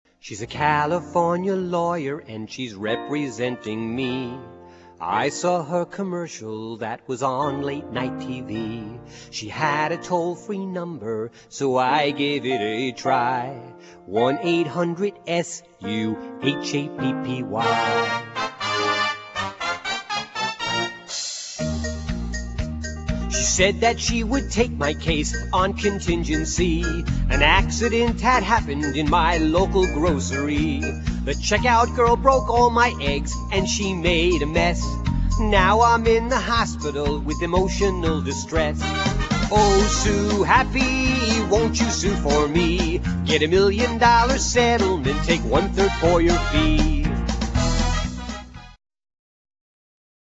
--comedy music